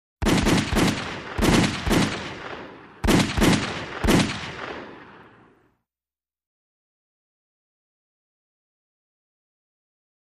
Automatic Weapon 2, Single & Multiple Bursts, In Echoey Canyon.